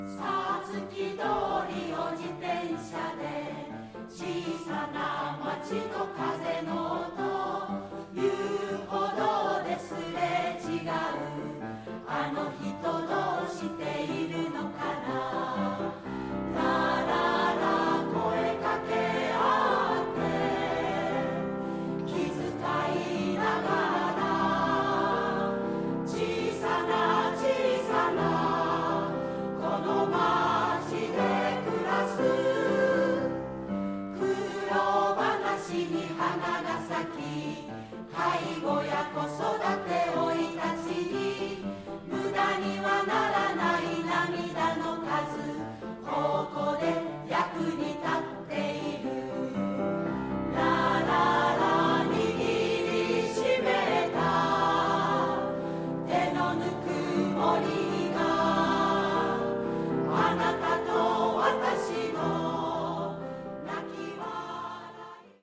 ピアノ
推敲を重ねた作品は魅力、厚みを増すものですね、何より演奏する人が楽しんで歌っているのがいいです。
曲も楽しくさわやか、いいかんじ。
２部へのアレンジも自然です。
曲もまたシンプルだが愛唱歌になる親しみを感じます。後半の伸びやかさが好感。